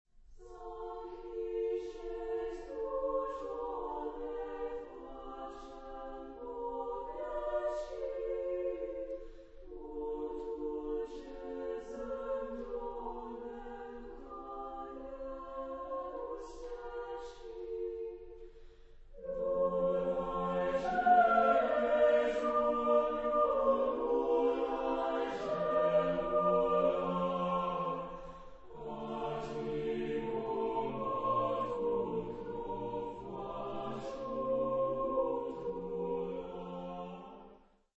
Genre-Style-Form: Sacred ; Lullaby ; Christmas carol
Mood of the piece: gentle ; slow
Type of Choir: SSATBB  (6 mixed voices )
Soloist(s): Soprane (2)  (2 soloist(s))
Tonality: F major